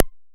Modular Perc 03.wav